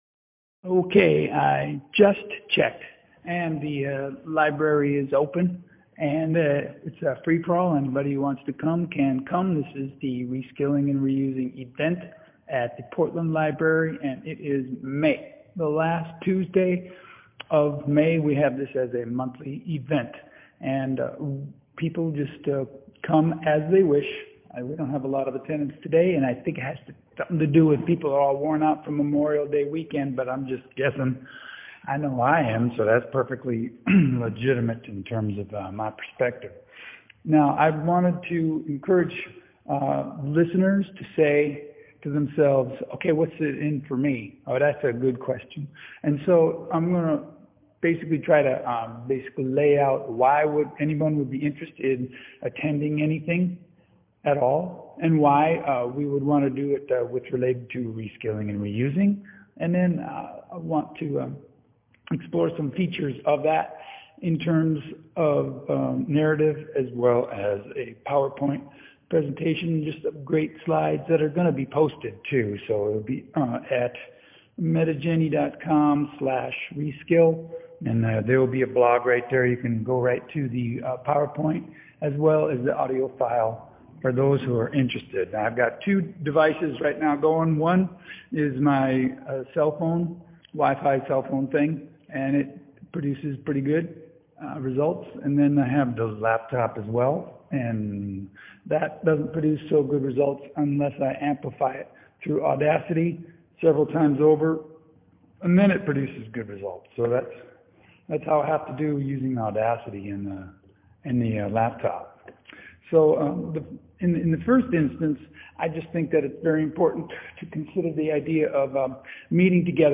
Last Tuesdays of the month at 6pm at the Portland Library in Louisville KY. Contents: All things Reskilling and Reusing, such as why do it, how to do it, when to do it, …you get the picture.